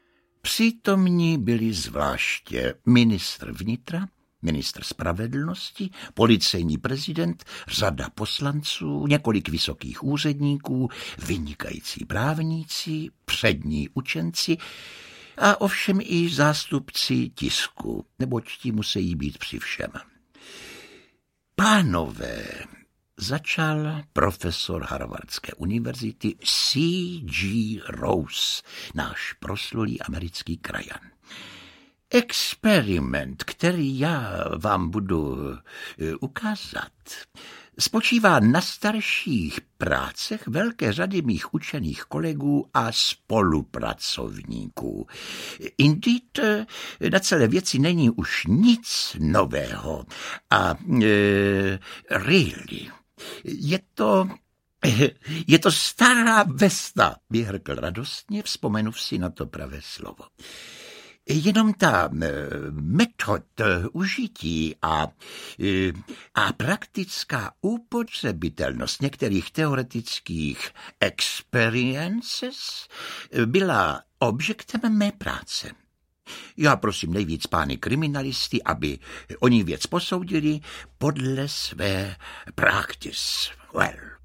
Povídky z jedné a z druhé kapsy audiokniha
Ukázka z knihy
Krásnou češtinu, laskavé vyprávěčství a také trochu "prvorepublikové" atmosféry zachycuje nahrávka vybraných Povídek z jedné a z druhé kapsy Karla Čapka. Literární skvosty interpretují mistři mluveného slova - pánové Ladislav Mrkvička, Josef Somr a Stanislav Zindulka.